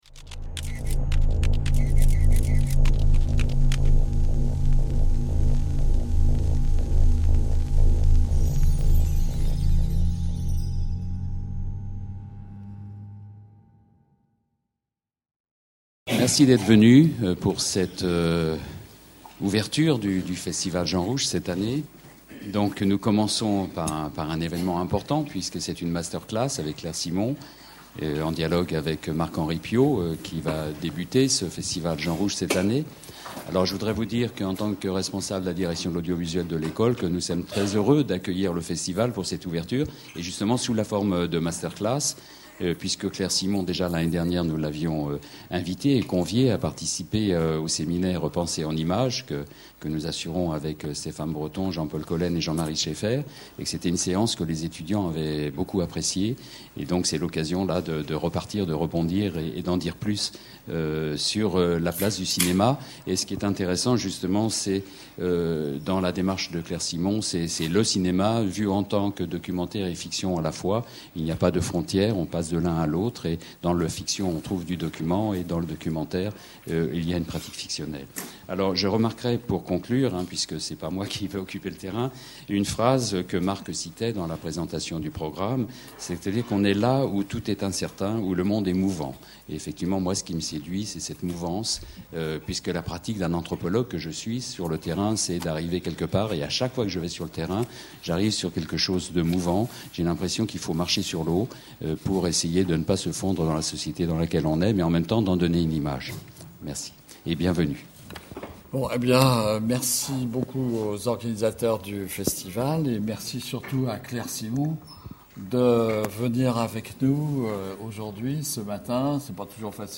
1/2 Master class de Claire Simon, réalisatrice.
Rencontre avec la réalisatrice Claire Simon